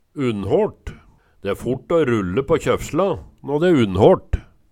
DIALEKTORD PÅ NORMERT NORSK unnhåLt is under tørr snø Eksempel på bruk De e fort å rulle på kjøfsla nå de e unnhåLt Tilleggsopplysningar Bygdearkivet